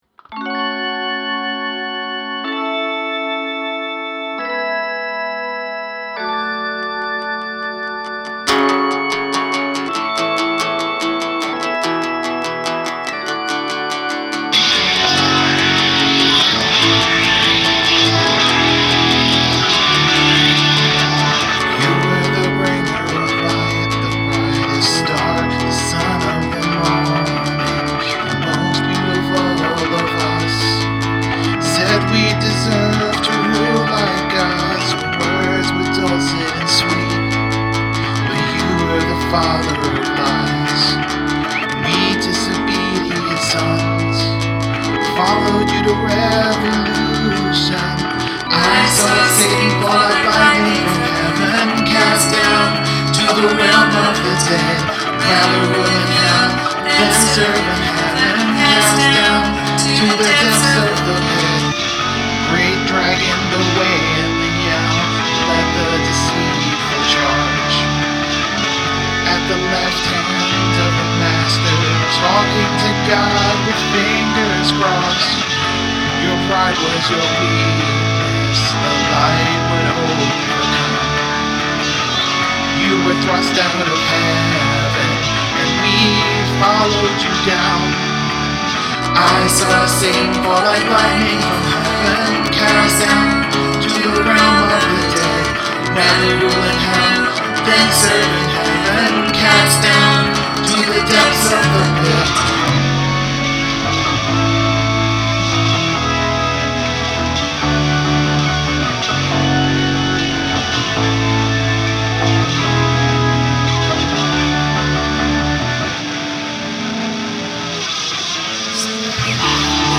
include a section of backwards singing or instrumentation